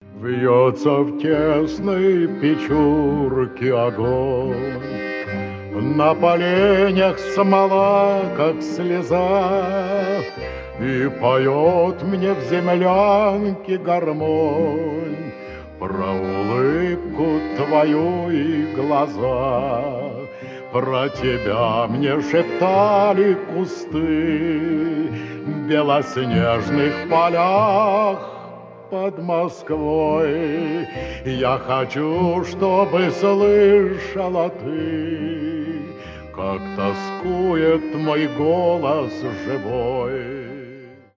ретро , аккордеон